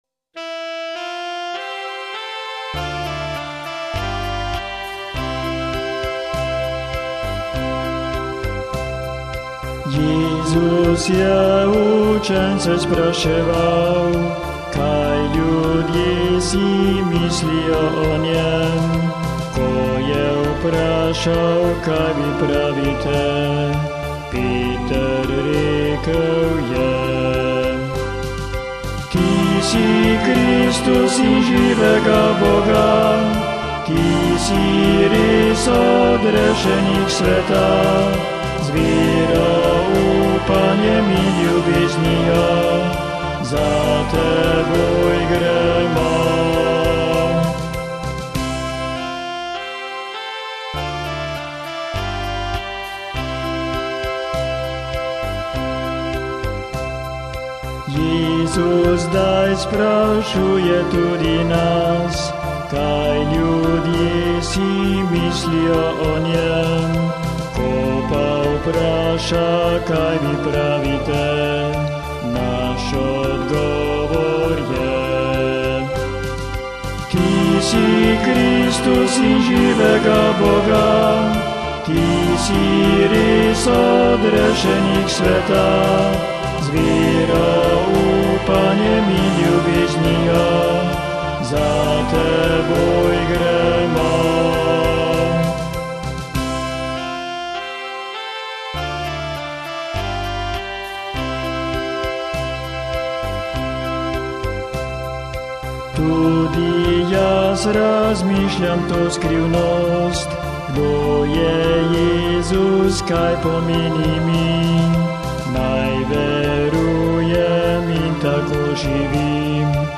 Posnetek :  DEMO 1 (za predstavitev, vaje...)